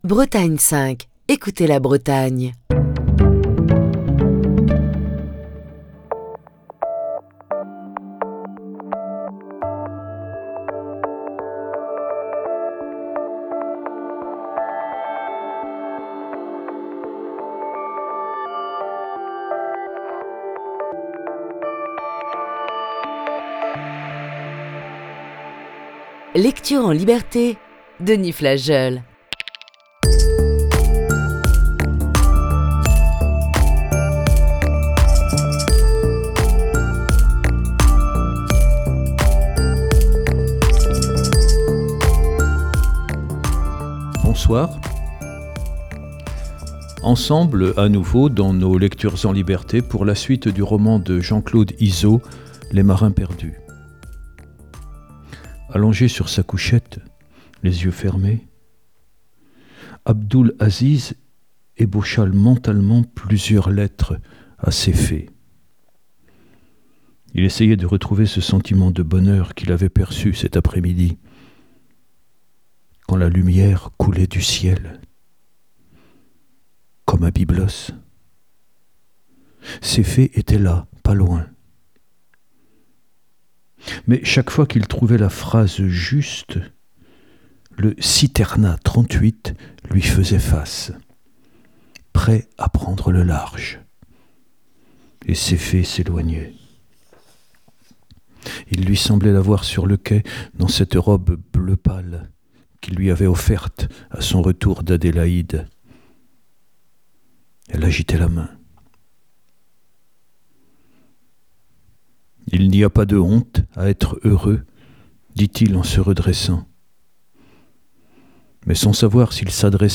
Voici ce soir la huitième partie de ce récit.